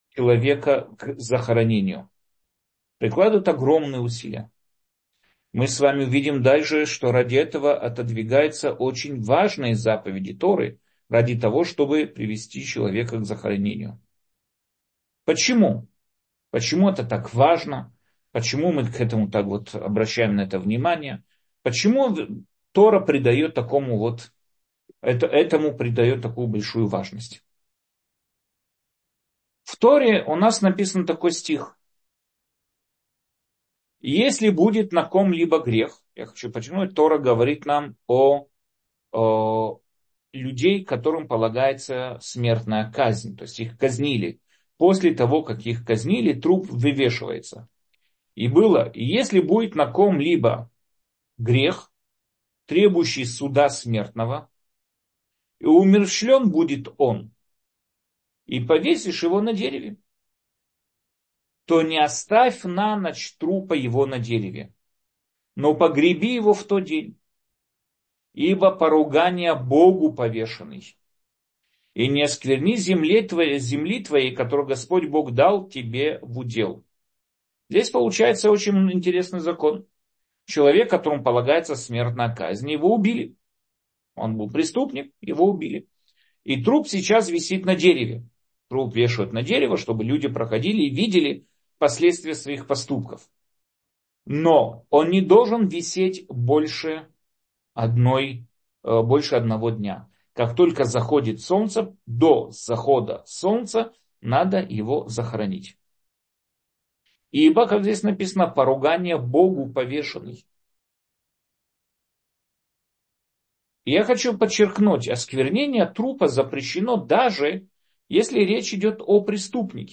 Аудиоуроки